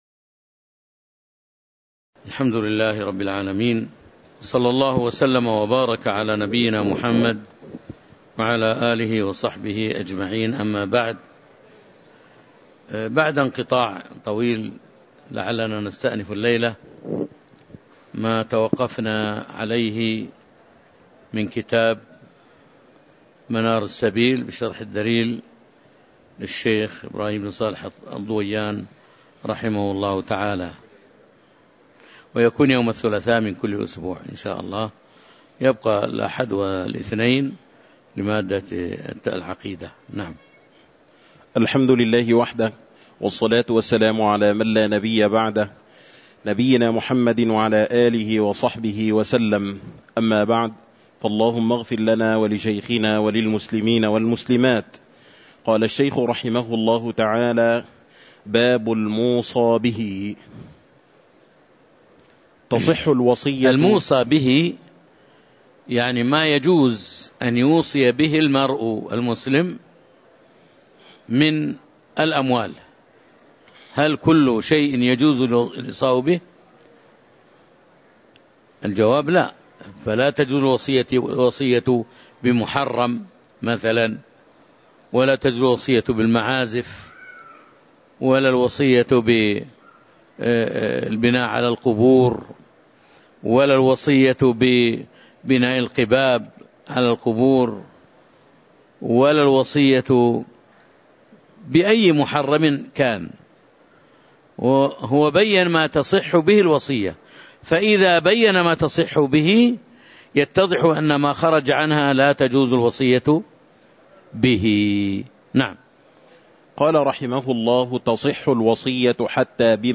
تاريخ النشر ١٦ صفر ١٤٤٦ هـ المكان: المسجد النبوي الشيخ